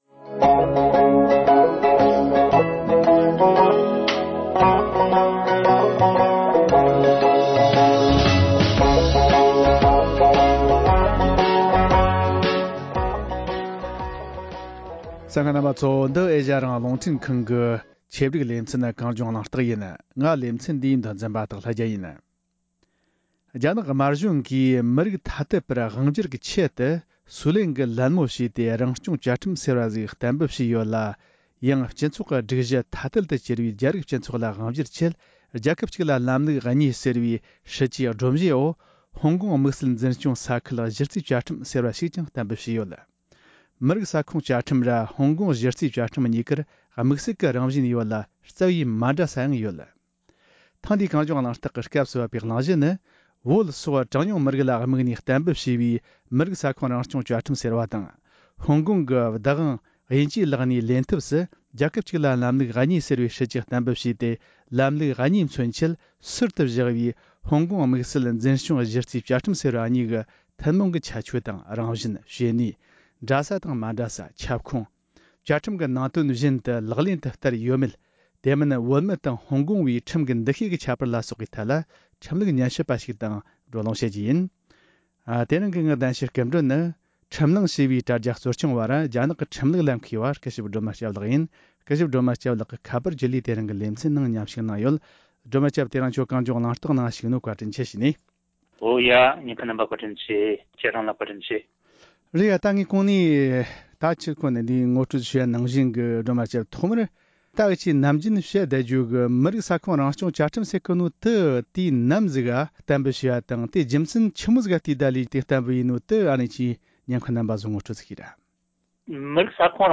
བོད་སོགས་གྲངས་ཉུང་མི་རིགས་ལ་དམིགས་པའི་མི་རིགས་ས་ཁོངས་རང་སྐྱོང་བཅའ་ཁྲིམས་དང་རྒྱལ་ཁབ་གཅིག་ལ་ལམ་ལུགས་གཉིས་ཟེར་བའི་སྲིད་ཇུས་གཏན་འབེབས་སོགས་ཀྱི་ཐད་གླེང་མོལ།